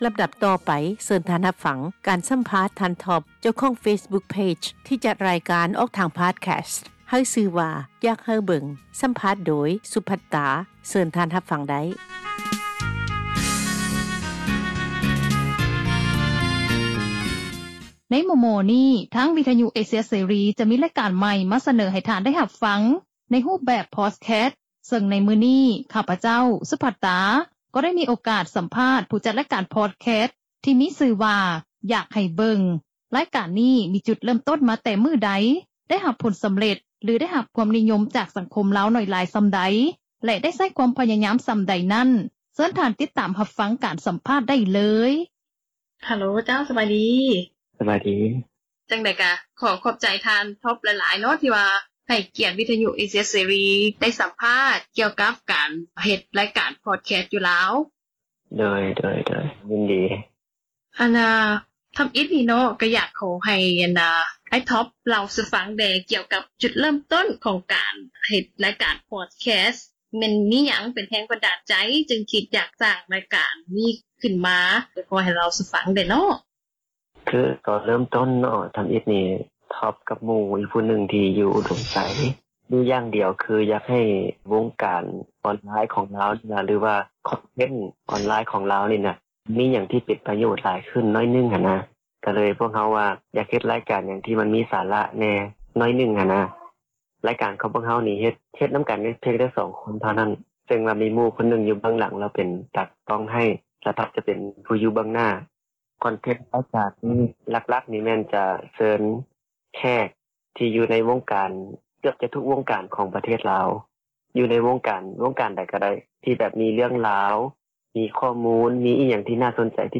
ຣາຍການ “ເມືອງລາວ ປະຈຳສັບປະດາ” ມື້ນີ້ ແມ່ນການ ສຳພາດ